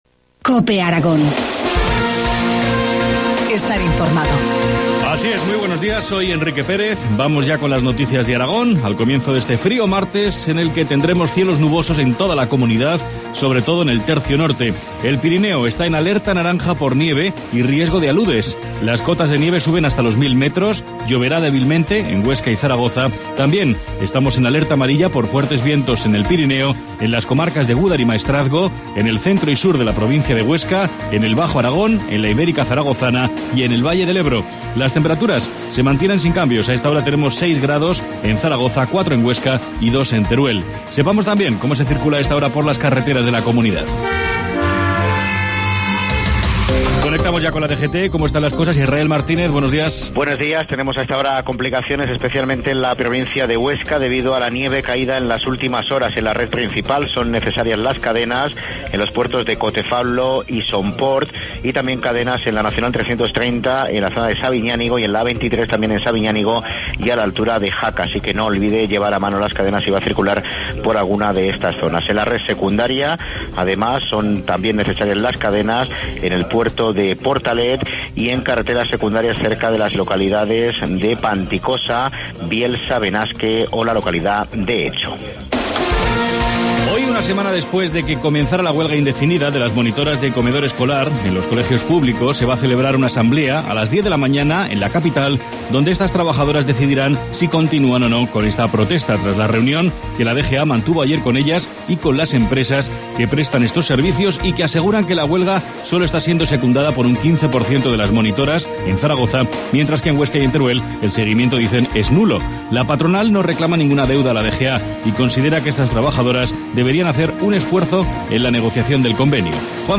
Informativo matinal, martes 15 de enero, 7.25 horas